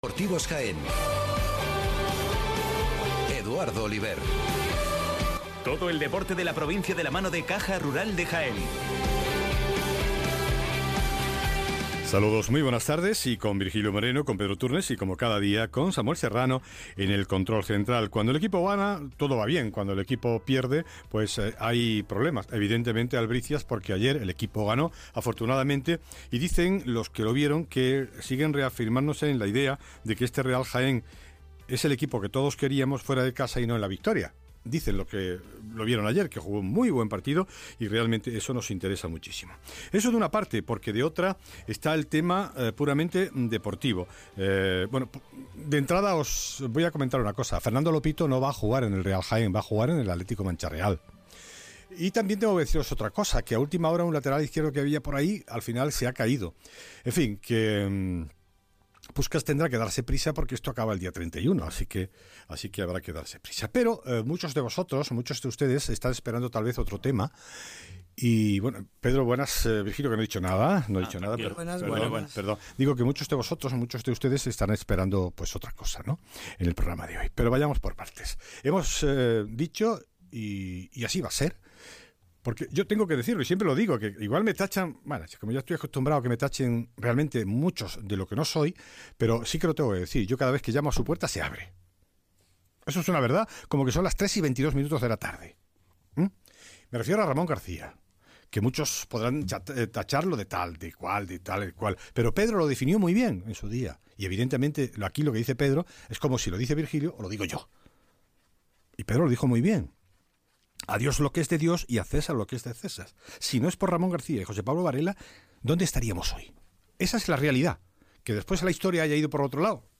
sobre una posible venta del club blanco en próximas fechas en los micrófonos de Ser Deportivos de Radio Jaén Cadena SER.